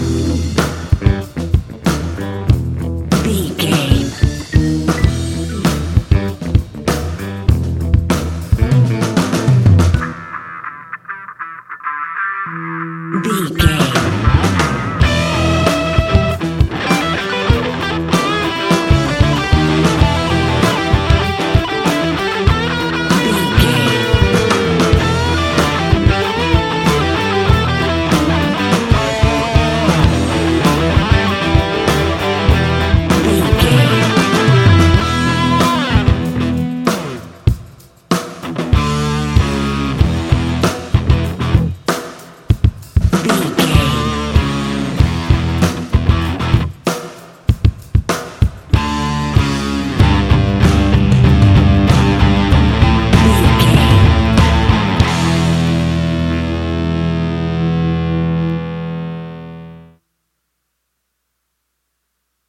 Ionian/Major
E♭
hard rock
heavy rock
distortion
instrumentals